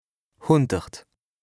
2APRESTA_OLCA_LEXIQUE_INDISPENSABLE_BAS_RHIN_118_0.mp3